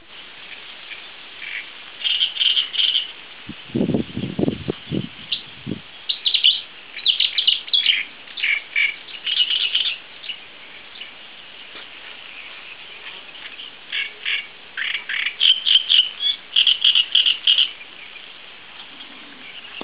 Database dei canti ed altre vocalizzazioni
Cannareccione e Usignolo di fiume (prova)    Nuova Discussione
Prova di registrazione con la mia fotocamera
Comunque come ho titolato si tratta di Cannareccione e Usignolo di fiume